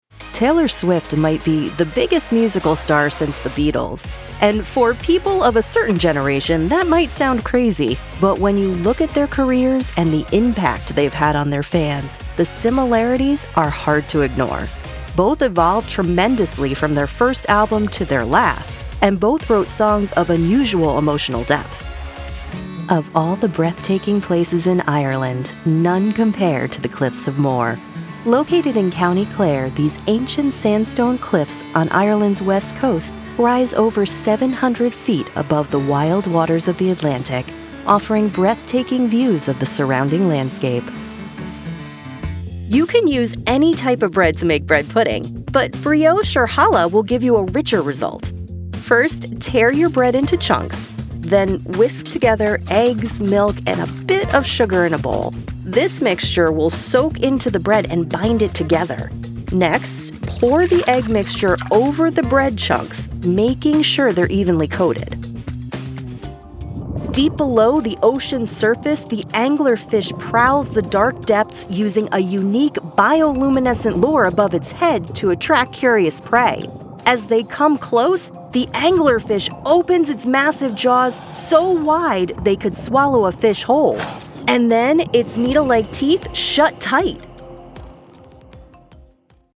Narration Demo
English (North American)
Young Adult
Middle Aged